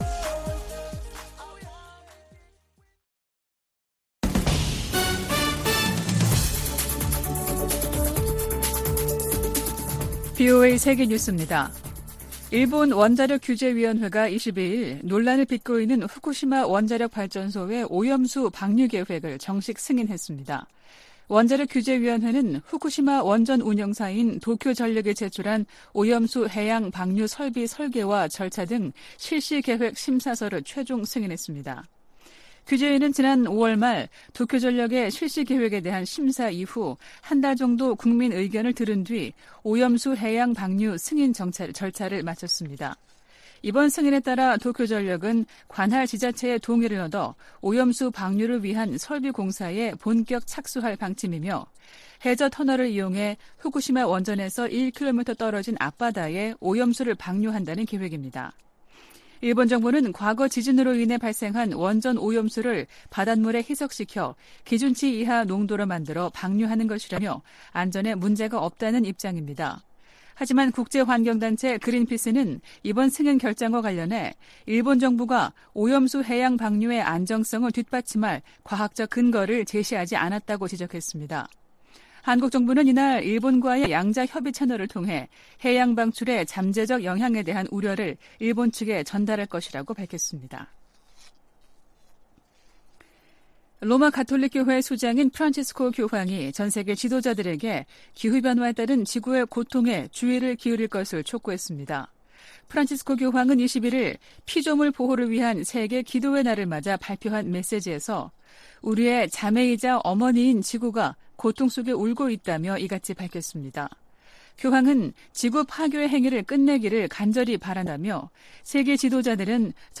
VOA 한국어 아침 뉴스 프로그램 '워싱턴 뉴스 광장' 2022년 7월 23일 방송입니다. 한국 국방부는 대규모 미-한 연합연습과 야외기동훈련을 올해부터 부활시킬 방침이라고 밝혔습니다. 미국은 한국과 일본의 핵무장을 절대 지지하지 않을 것이라고 고위 관리가 전망했습니다. 미 공화당 상원의원들이 로이드 오스틴 국방장관에게 인도태평양 지역 미사일 배치를 위해 한국 등 동맹국들과 협력하라고 촉구했습니다.